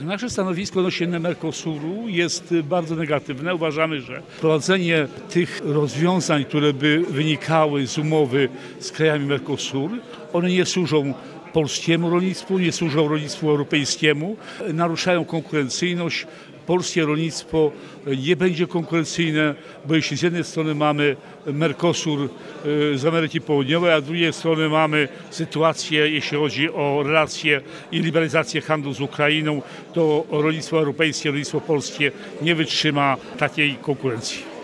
Stanowisko dot. umowy o wymianie i współpracy handlowej między UE a krajami Mercosur musi być jednoznaczne, krytyczne – mówił podczas spotkania z rolnikami minister Czesław Siekierski.